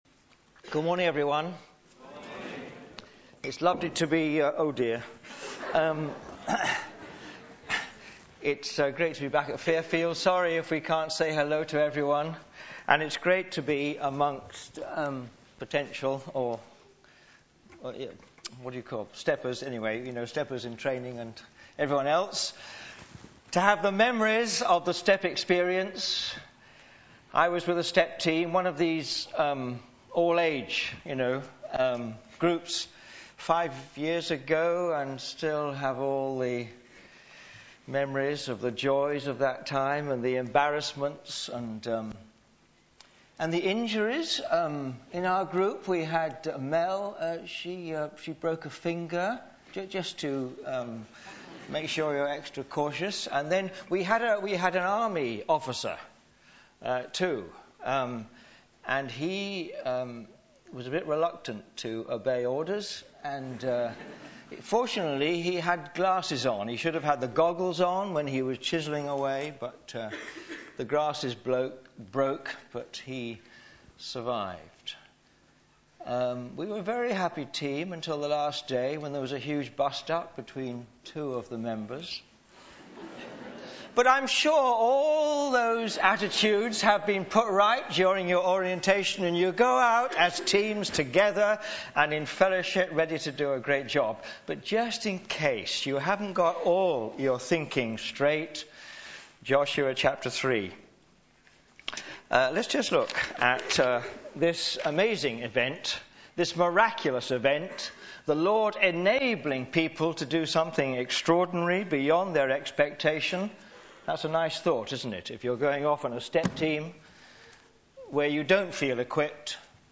Media for Sunday Service on Sun 27th Feb 2011 10:00
The Walk of Faith Sermon